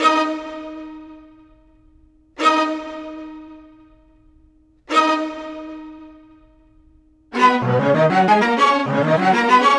Rock Star - Strings.wav